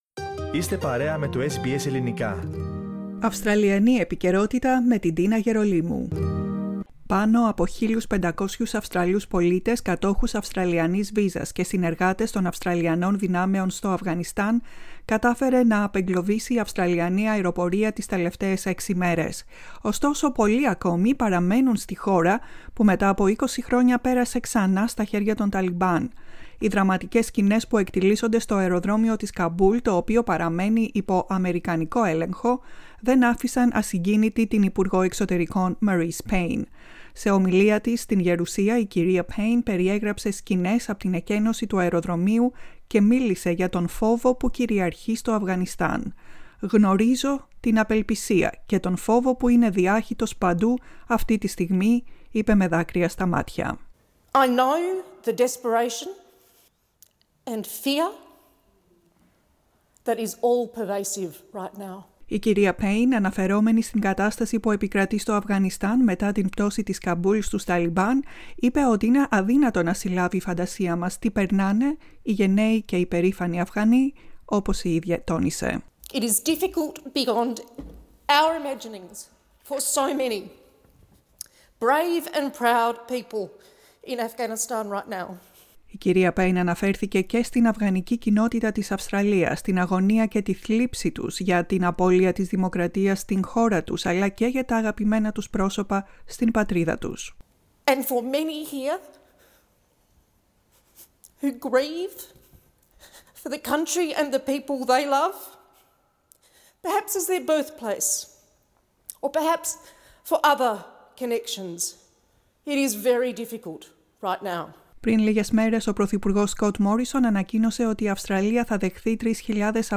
Στην εποχή του Μπομπ Χοκ και της εξέγερσης στην πλατεία Τιενανμέν παρέπεμψε η ομιλία της υπουργού εξωτερικών MarisePayne στη γερουσία, Με δάκρυα στα μάτια μίλησε για τον φόβο και την απελπισία των Αφγανών. Χθες βράδυ, πάνω απο 600 άτομα απομακρύνθηκαν απ΄το Αφγανιστάν με αεροσκάφη της Αυστραλίας και της Νέας Ζηλανδίας.